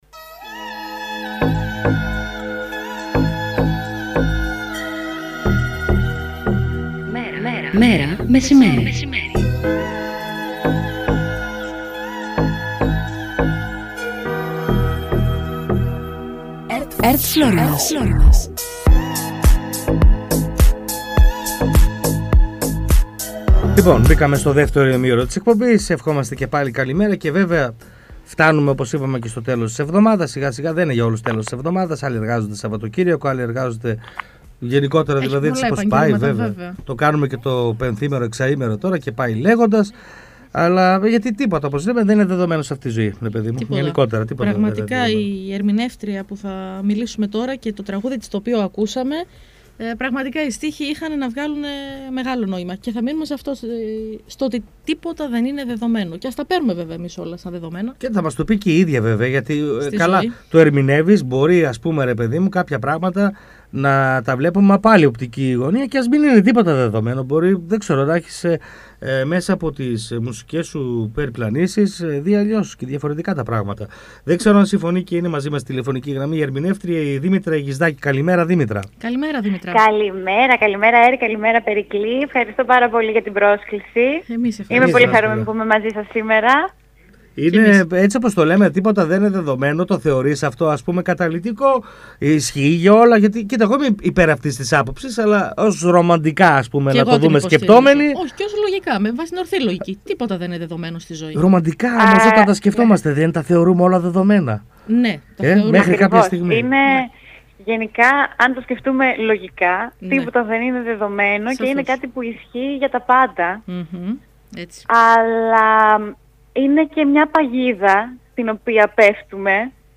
«Μέρα μεσημέρι» Καθημερινό ραδιοφωνικό μαγκαζίνο που ασχολείται με ρεπορτάζ της καθημερινότητας, παρουσιάσεις νέων δισκογραφικών δουλειών, συνεντεύξεις καλλιτεχνών και ανάδειξη νέων ανθρώπων της τέχνης και του πολιτισμού.